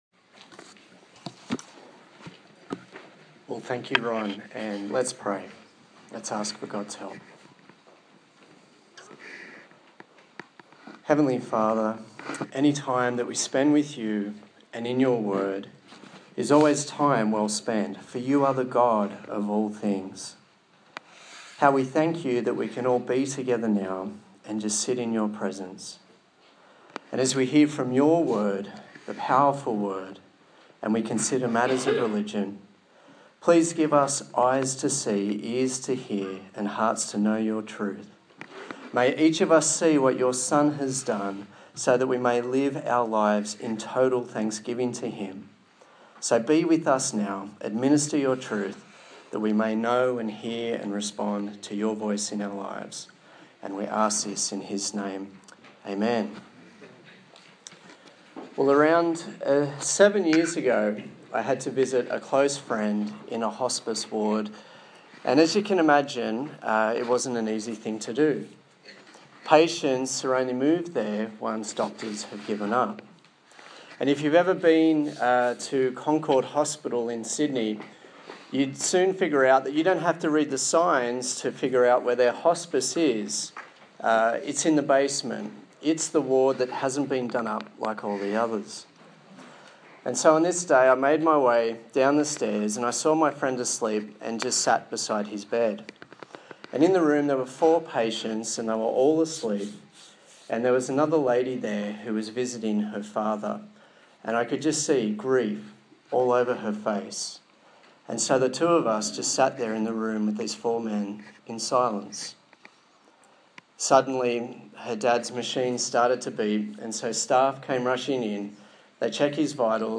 24/05/2015 The Shadow of Religion Preacher
Colossians Passage: Colossians 2:13-23 Service Type: Sunday Morning